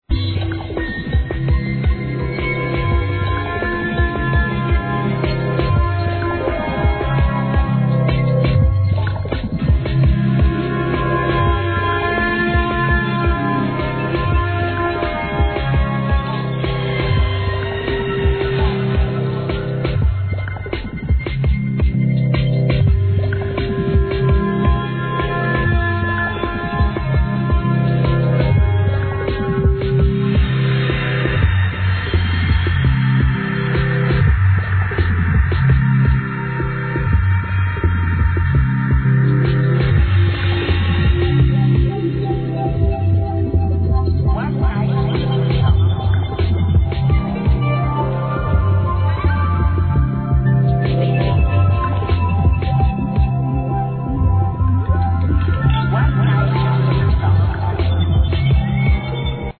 HIP HOP/R&B
更にダウンテンポ＆チルアウト感に磨きがかかった2001年名作!!